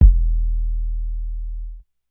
MB Kick (37).wav